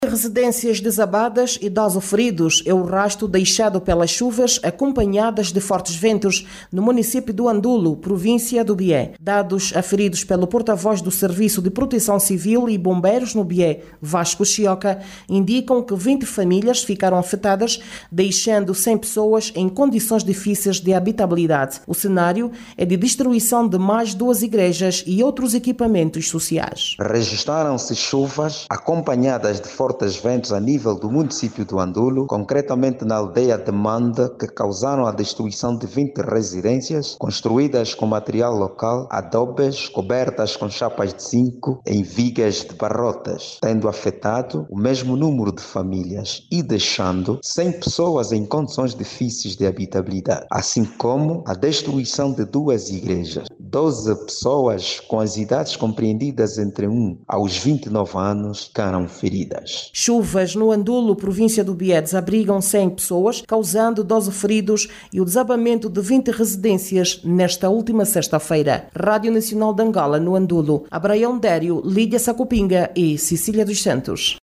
No município do Andulo, província do Bié, mais de cem famílias ficaram desalojadas em consequência da forte chuva que provocou o desabamento de 20 residências. Dezasseis pessoas ficaram feridas, como nos conta na reportagem